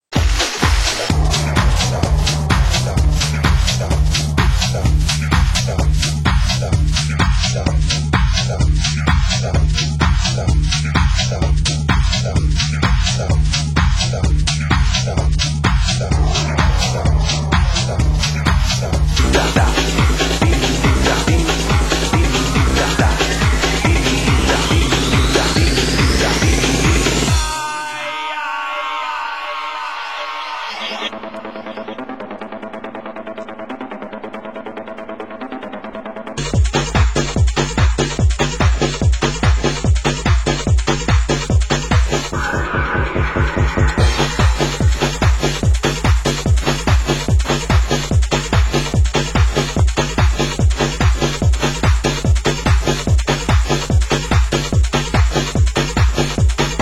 Genre: Hard House